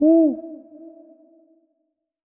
owl_hoot_with_reverb_01.wav